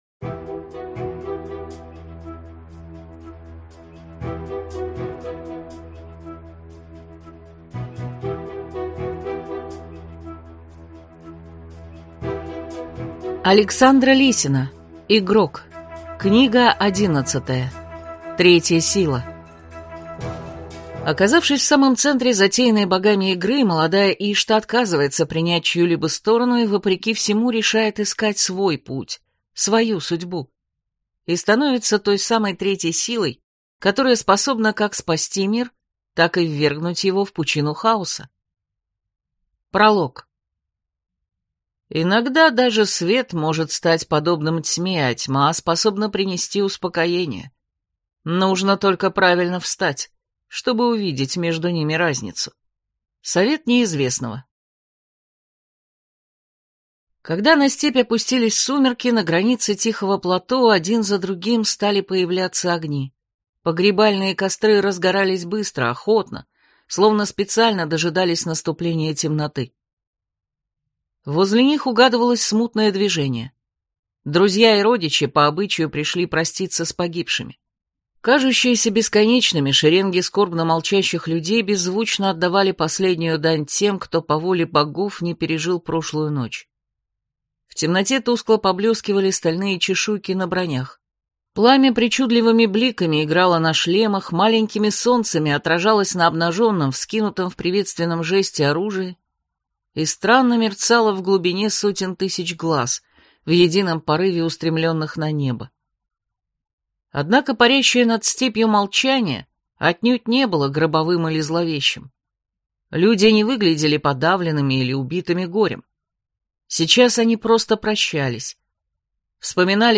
Аудиокнига Третья сила | Библиотека аудиокниг
Прослушать и бесплатно скачать фрагмент аудиокниги